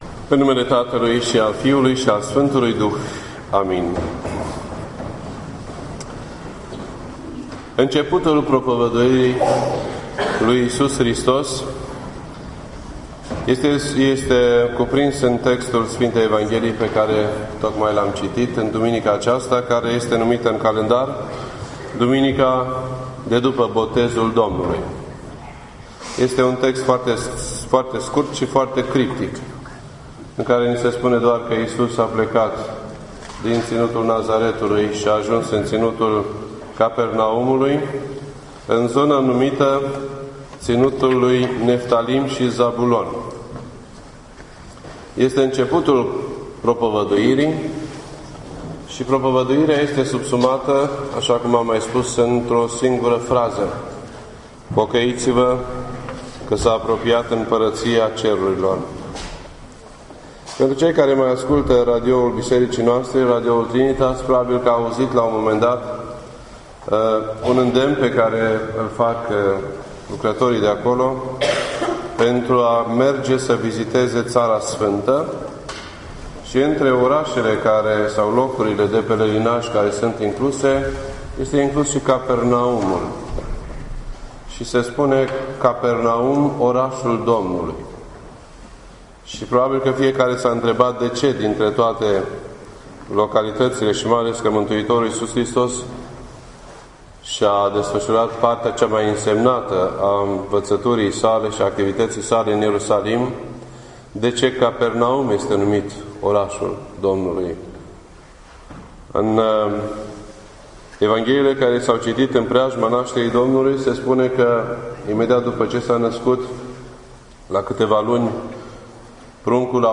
This entry was posted on Sunday, January 13th, 2013 at 7:51 PM and is filed under Predici ortodoxe in format audio.